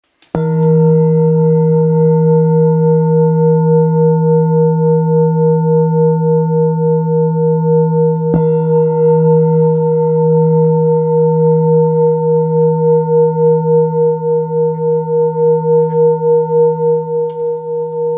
Jambati Singing Bowl Singing Bowl, Buddhist Hand Beaten, Jambhala Carved, Silver Plated
Material Seven Bronze Metal
Jambati Bowl is one of the sorts of Singing Bowl.
It's planned in a U-bended shape and has a thick edge.
It can discharge an exceptionally low dependable tone.